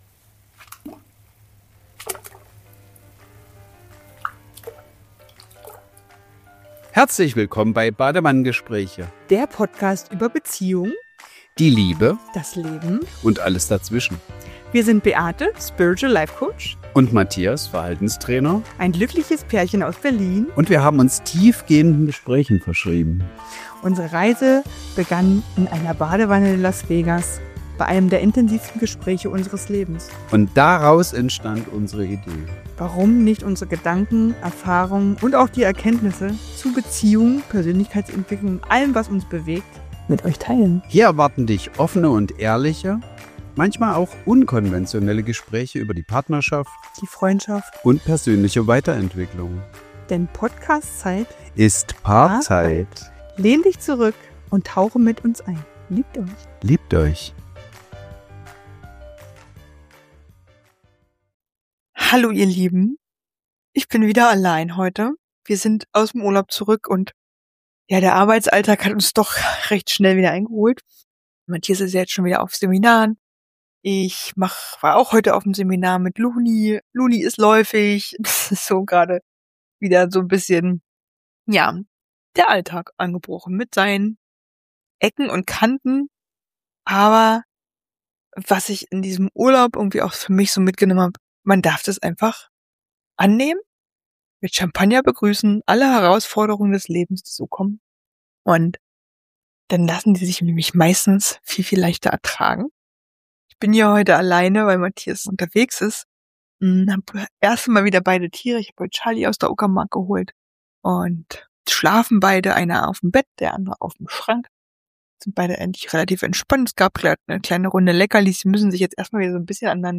In dieser Solo-Folge von Badewannengespräche spreche ich über genau diese inneren Wendepunkte.